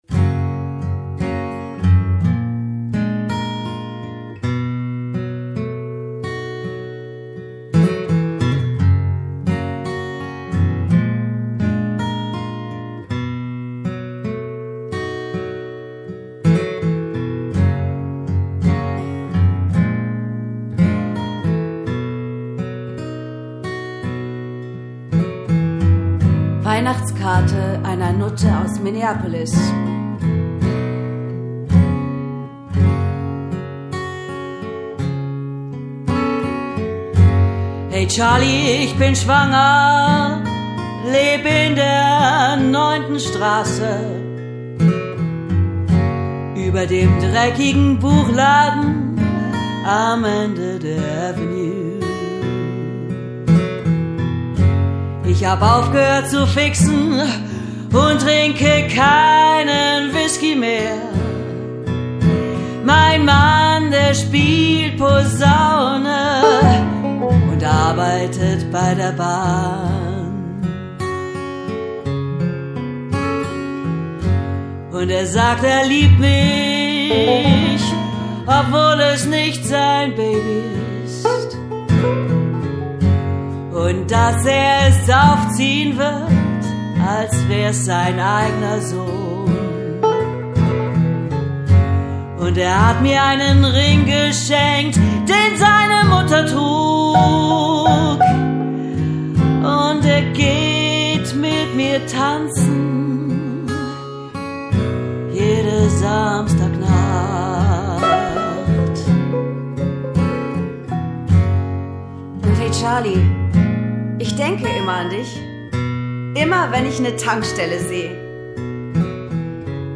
Gesang
A-Gitarren, E-Gitarren, Banjo, Blues-Harp & Percussion